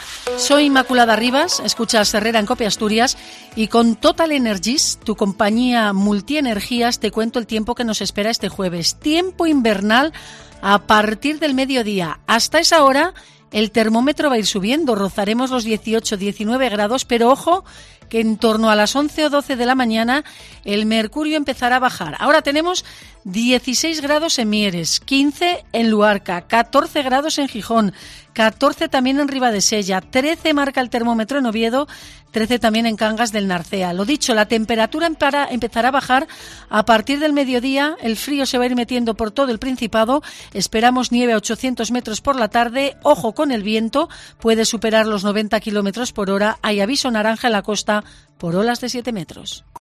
Así hemos contado en COPE el cambio del tiempo por la llegada de un frente frío